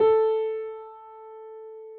piano_057.wav